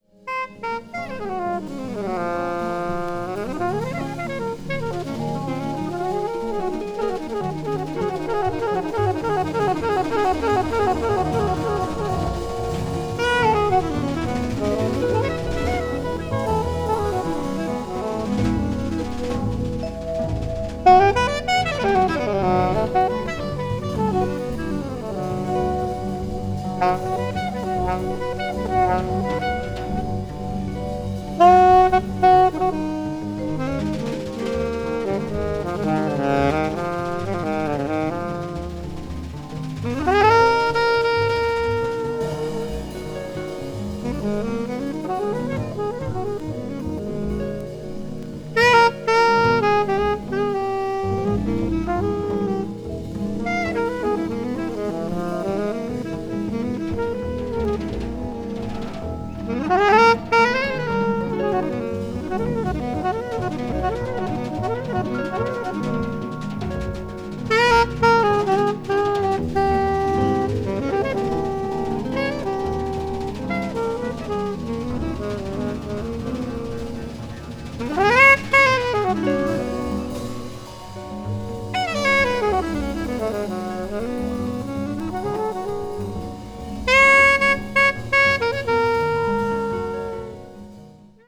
media : EX/EX(わずかにチリノイズが入る箇所あり,再生音に影響ないごく薄い擦れ1本あり)